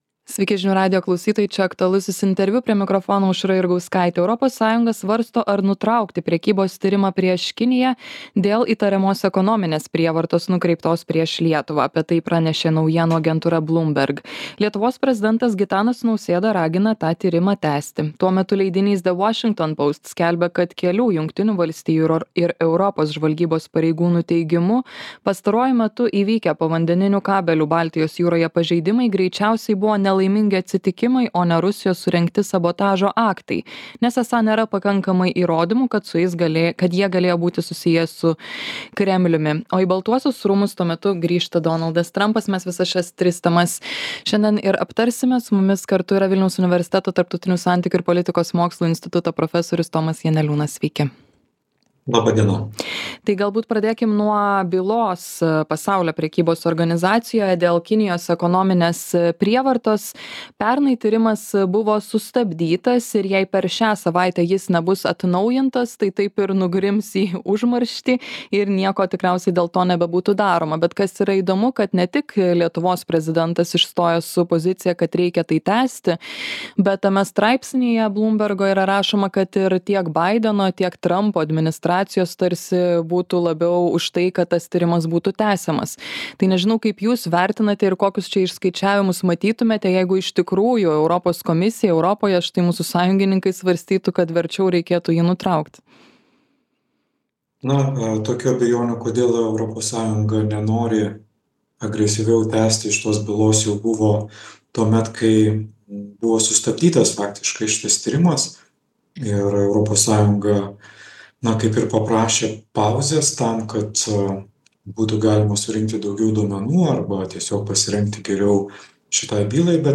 Aktualusis interviu